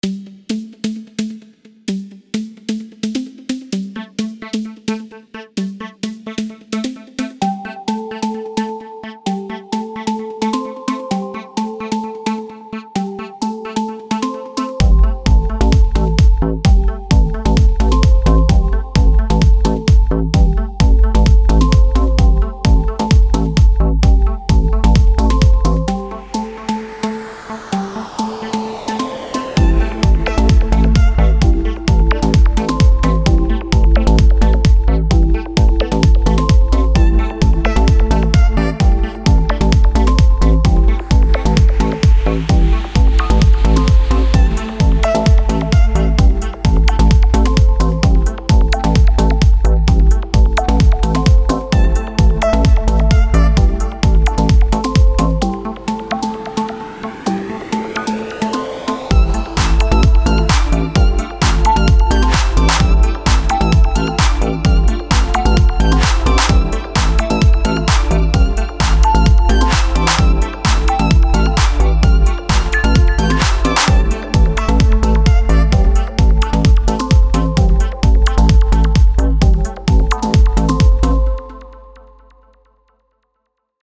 Скачать Минус
Стиль: Electro House